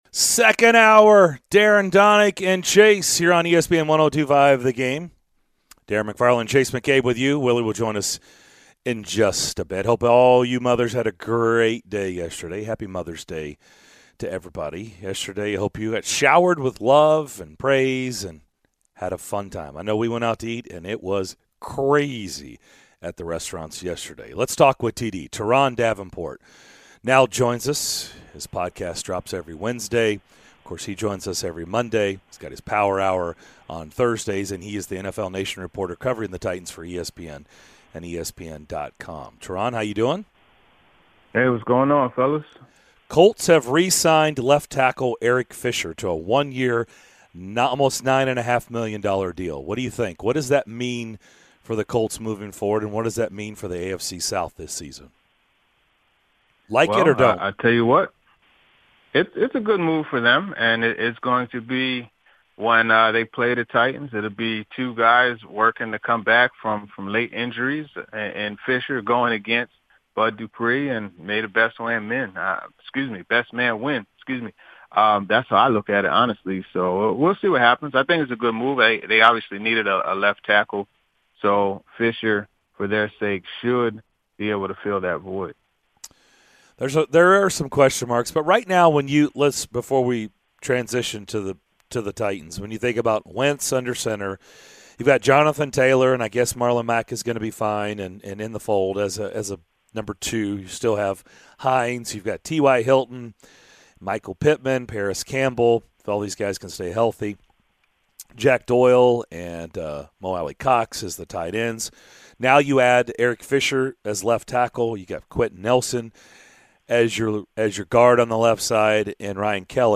the guys and the callers discuss what they learned from the sports weekend in This Is What I Know and more during the second hour of Monday's show!